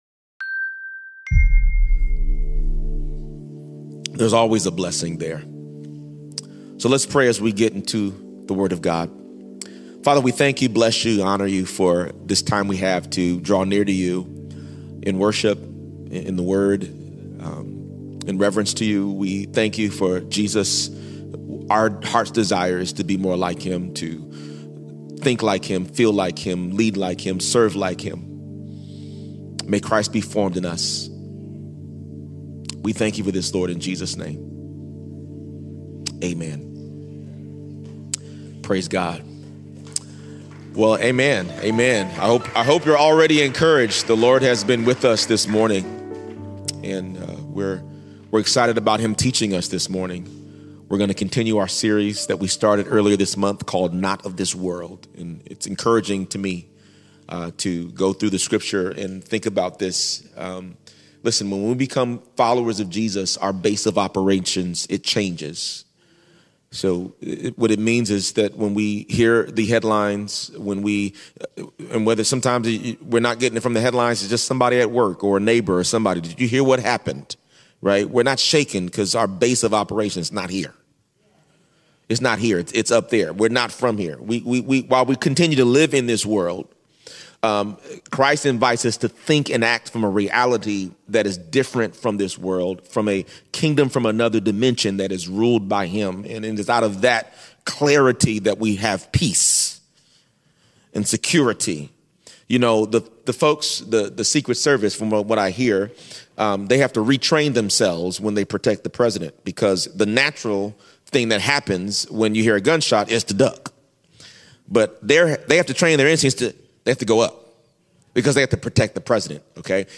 Latest Sermon “Not of This World” Pt 3